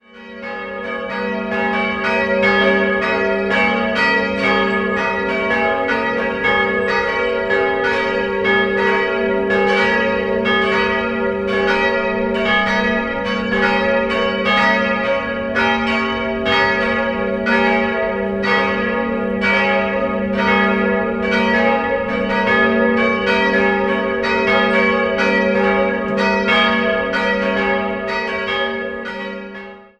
Jahrhundert zurückdatieren. 1852 wurden die heutigen Seitenaltäre aufgestellt und im Jahr 1919 verlängerte man das Gotteshaus. 3-stimmiges Geläut: as'-b'-des'' Die große Glocke wurde 1922 von der Firma Ulrich in Kempten gegossen, die beiden anderen stammen von Czudnochowsky (Erding) aus dem Jahr 1951.